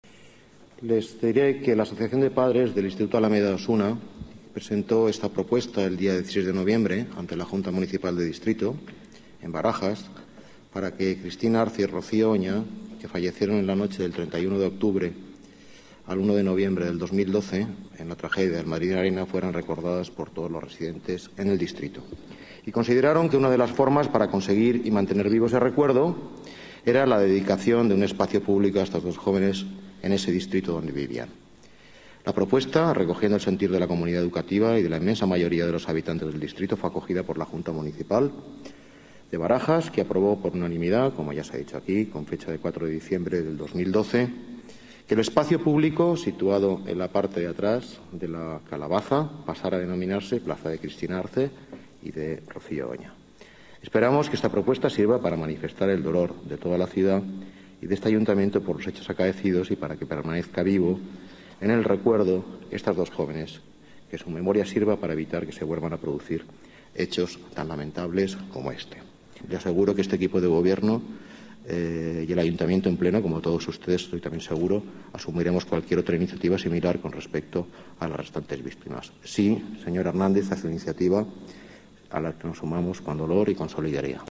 Nueva ventana:Declaraciones del delgado de Las Artes, Deportes y Turismo, Fernando Villalonga